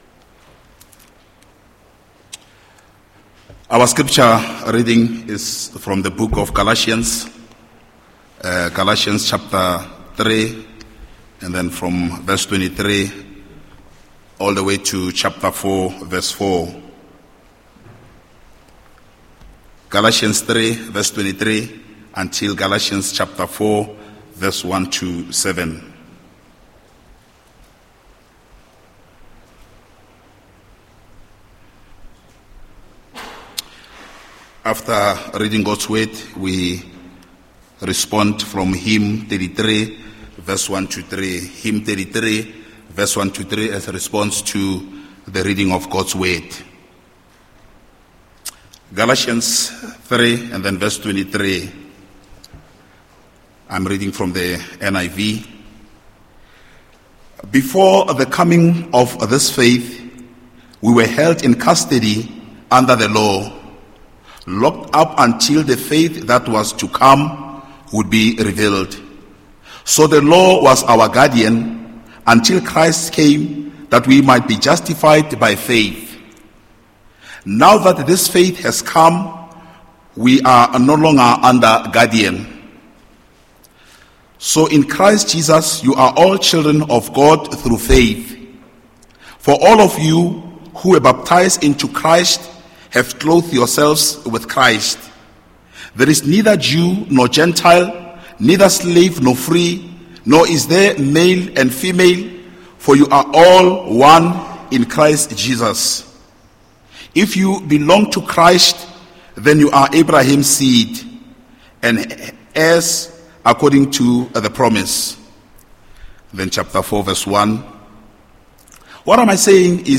Preek: Galasiërs 4:4-5